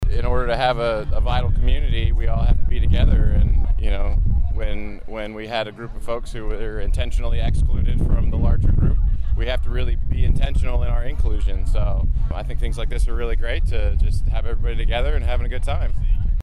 City Commissioner Peter Oppelt spoke on the importance of supporting the Juneteenth holiday…